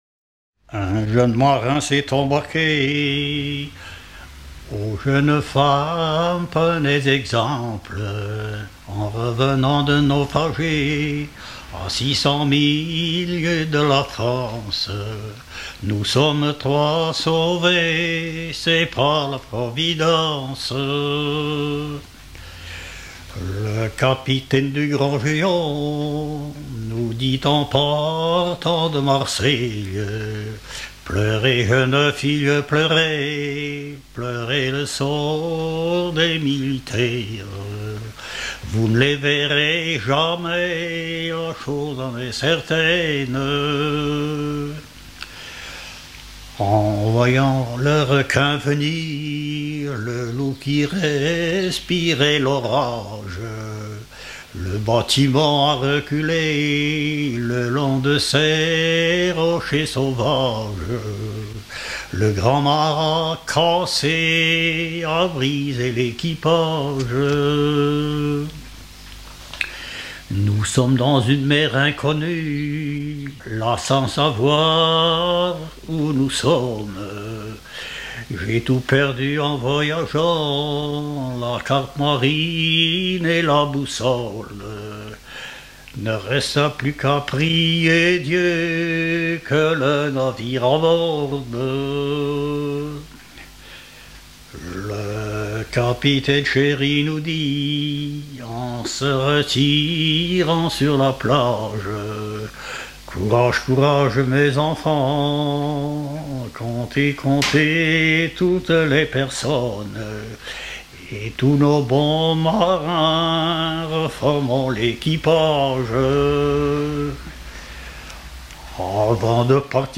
Localisation Givrand
Genre strophique